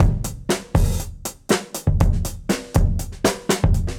Index of /musicradar/dusty-funk-samples/Beats/120bpm